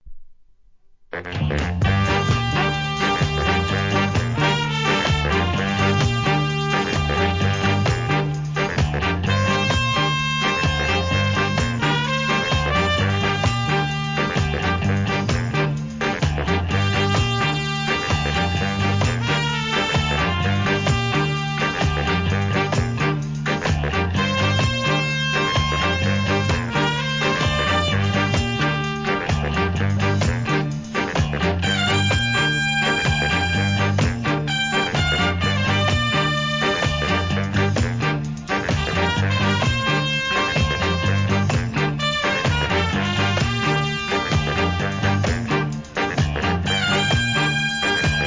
¥ 2,200 税込 関連カテゴリ REGGAE 店舗 数量 カートに入れる お気に入りに追加